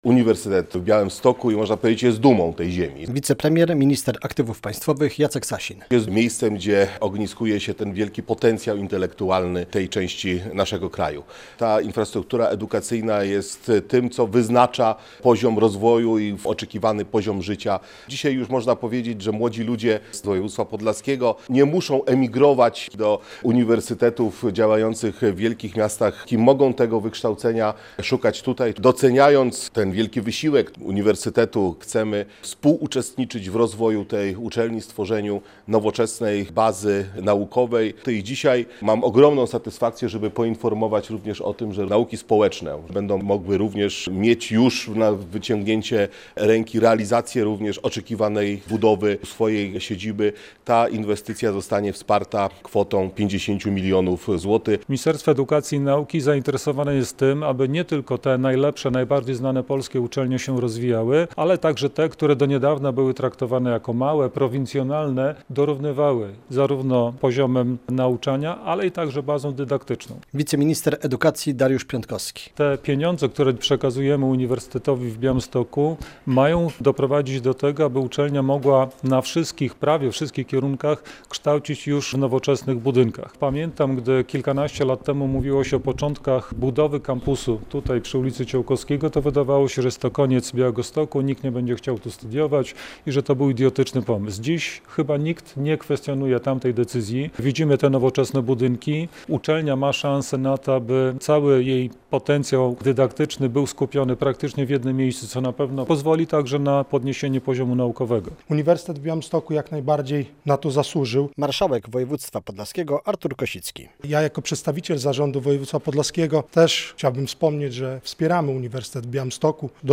Rozbudowa kampusu UwB - relacja
Polska wschodnia zasługuje na nowoczesną infrastrukturę edukacyjną. Tak by mieszkańcy regionu mogli na miejscu kształcić się na doskonale wyposażonych uczelniach - mówił na konferencji prasowej Jacek Sasin.